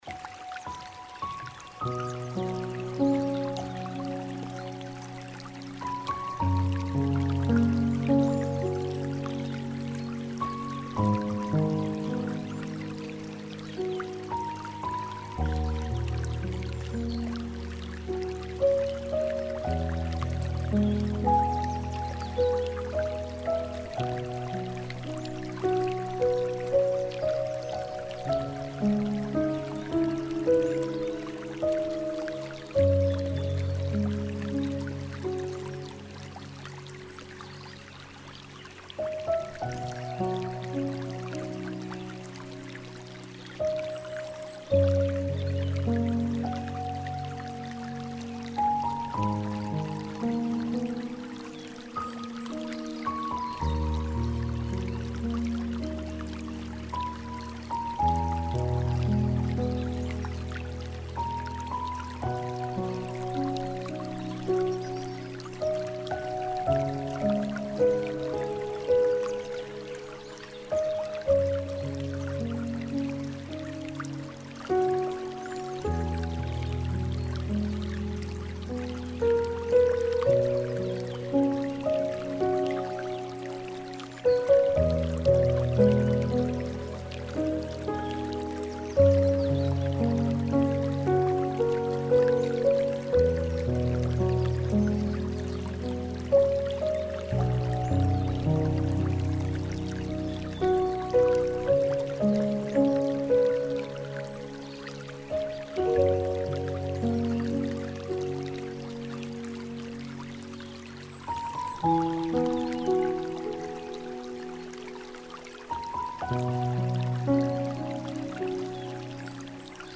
Spa & Relaxation
Sons Relaxants de Spa